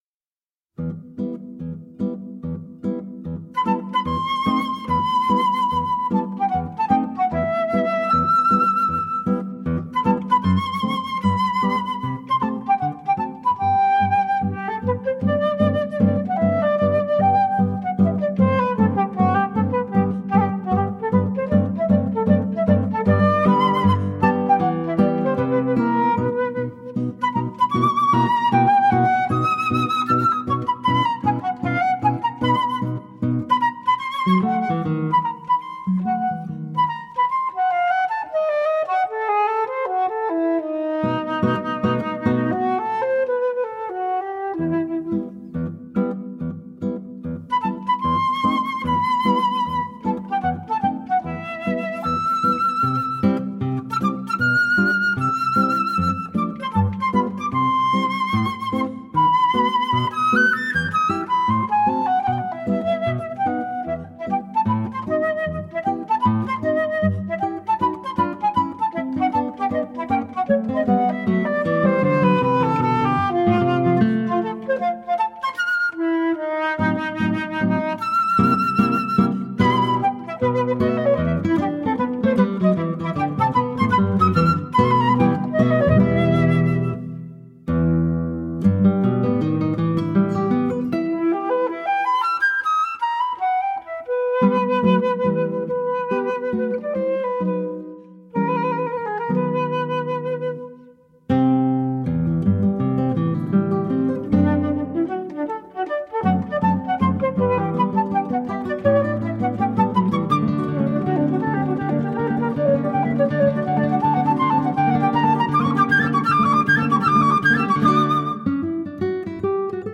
Lively flute/guitar duo.